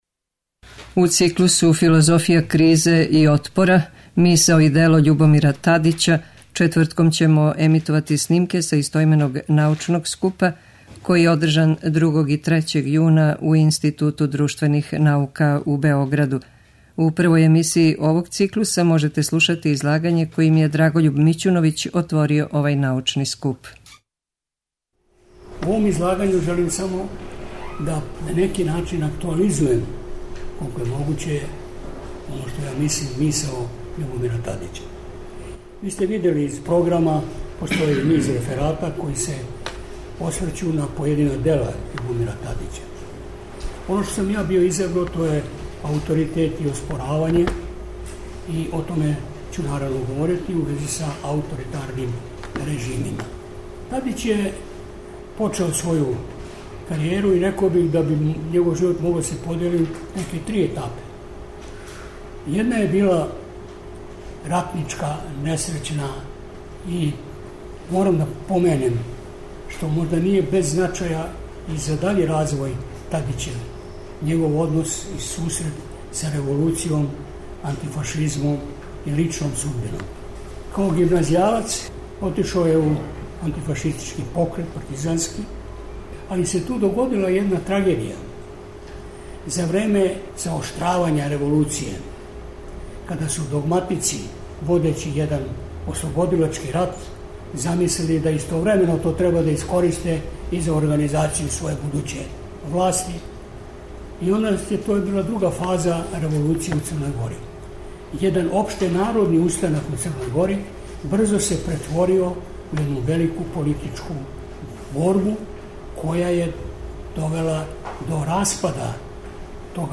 У циклусу ФИЛОЗОФИЈА КРИЗЕ И ОТПОРА: МИСАО И ДЕЛО ЉУБОМИРА ТАДИЋА четвртком ћемо емитовати снимке са истоименог научног скупа, који је ордржан 2. и 3. јуна у Институту друштвених наука у Београду. У првој емисији овог циклуса можете слушати излагање којим је Драгољуб Мићуновић отворио овај научни скуп.